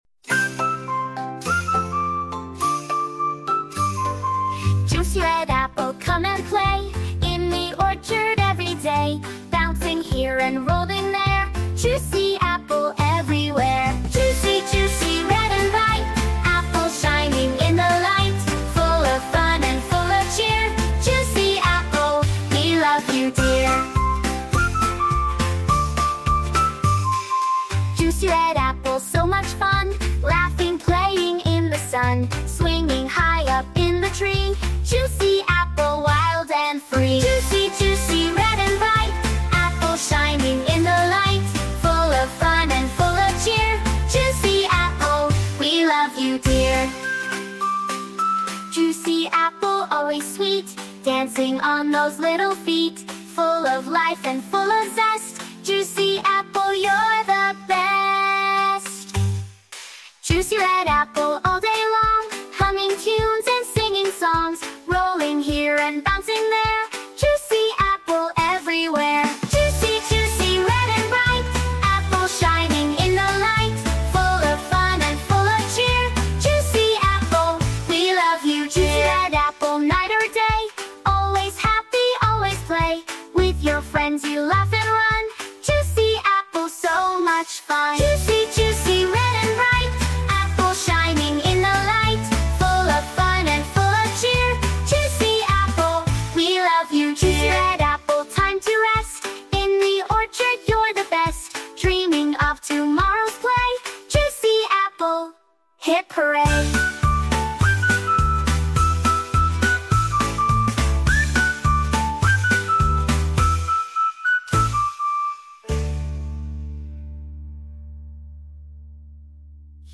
Nursery Rhymes & Kids Songs